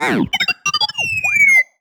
sci-fi_driod_robot_emote_23.wav